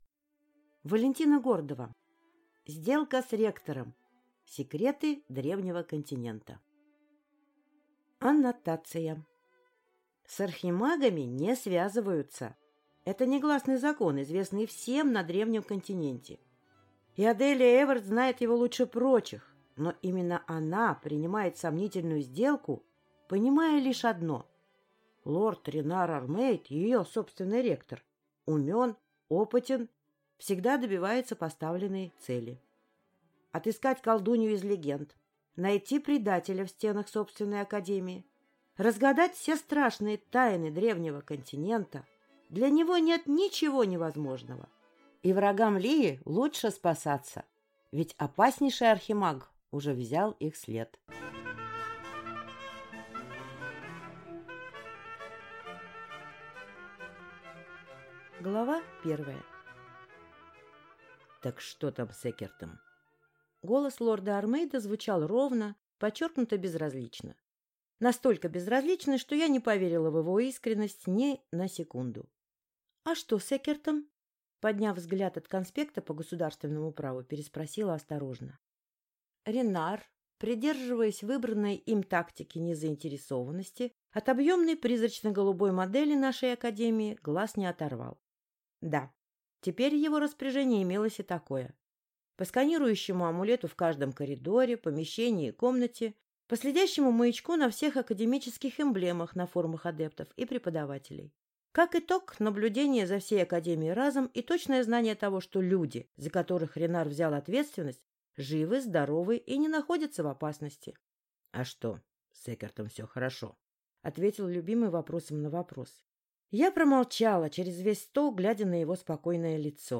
Аудиокнига Сделка с ректором. Секреты древнего континента | Библиотека аудиокниг